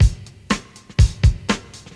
• 121 Bpm Modern Breakbeat Sample A# Key.wav
Free breakbeat sample - kick tuned to the A# note. Loudest frequency: 1266Hz
121-bpm-modern-breakbeat-sample-a-sharp-key-LKF.wav